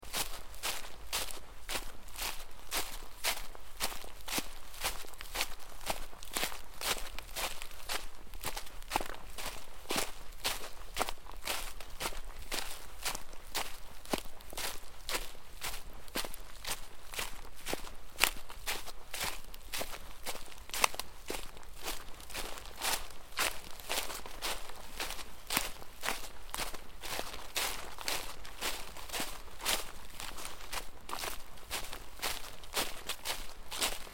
جلوه های صوتی
دانلود صدای جنگل 10 از ساعد نیوز با لینک مستقیم و کیفیت بالا
برچسب: دانلود آهنگ های افکت صوتی طبیعت و محیط دانلود آلبوم صدای جنگل از افکت صوتی طبیعت و محیط